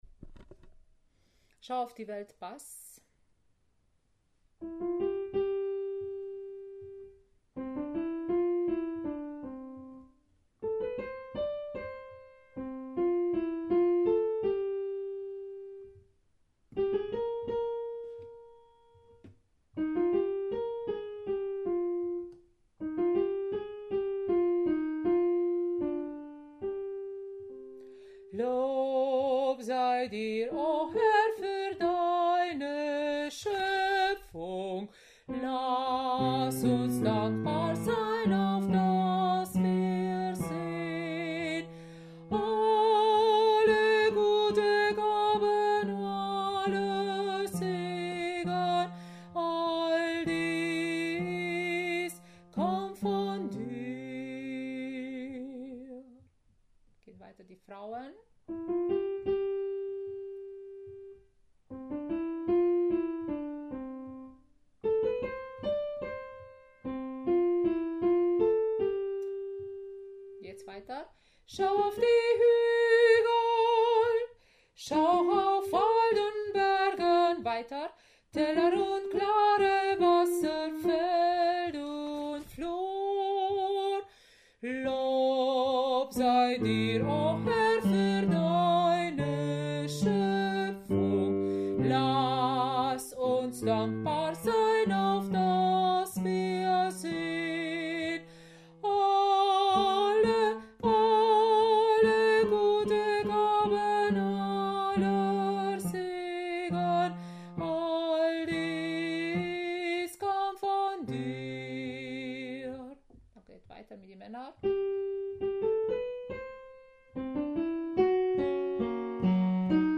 Schau auf die Welt – Bass
Schau-auf-die-Welt-Bass.mp3